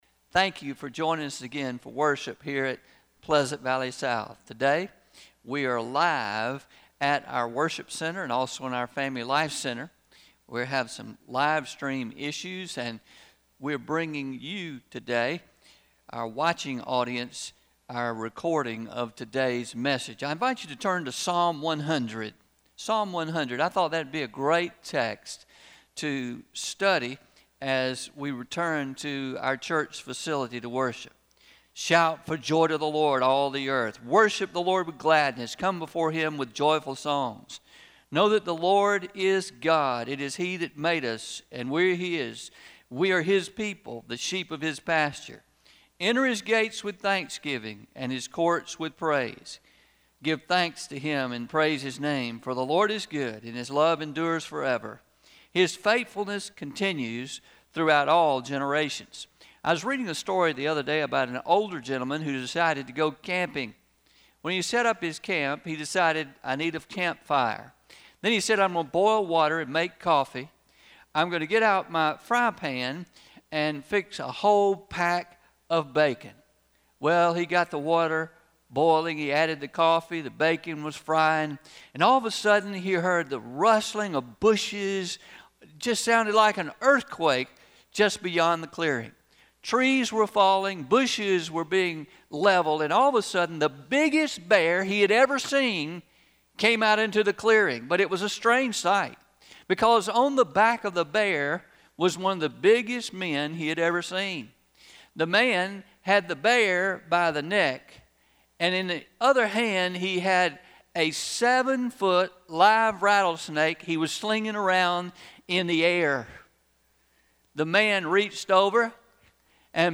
06-28-20am Sermon – Church with a Grateful Heart – Traditional – Pleasant Valley South Baptist Church